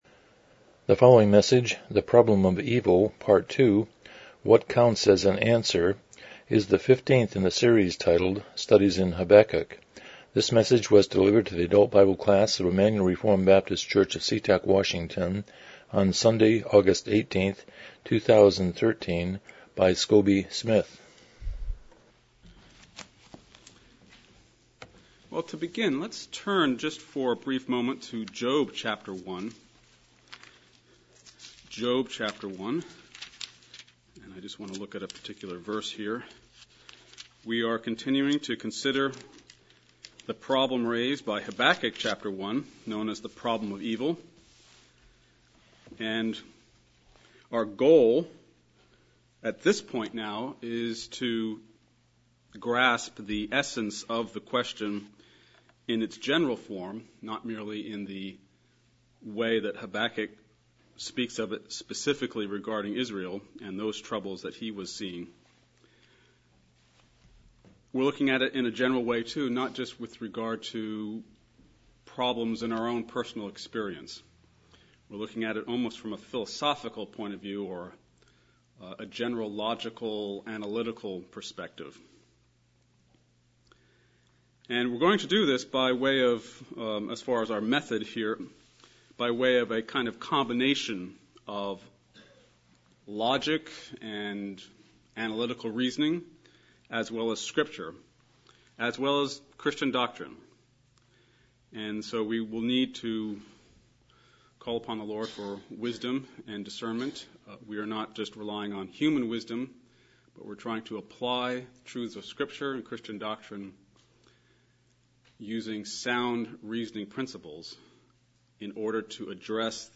Series: Studies in Habakkuk Service Type: Sunday School « 2 What does creation teach us about God? 36 The Sermon on the Mount